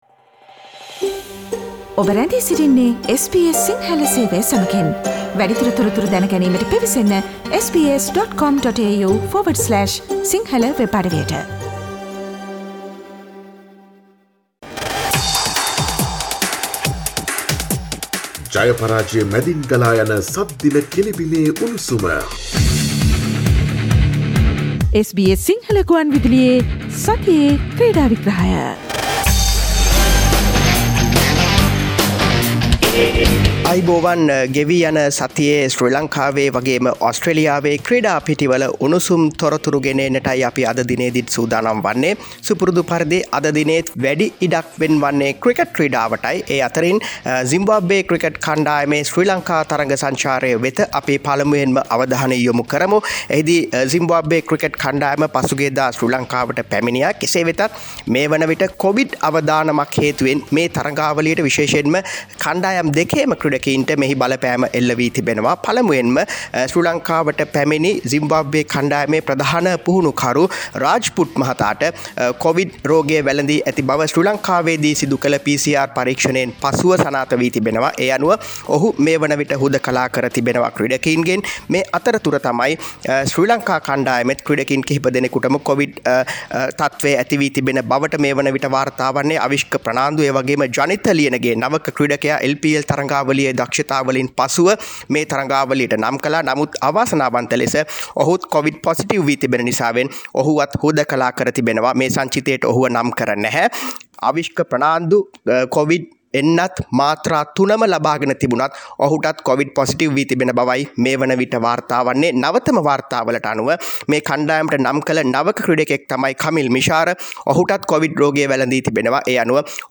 SBS Sinhala Sports Wrap with Sports Journalist